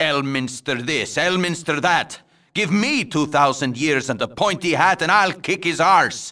vs_fedwinxx_cuss.wav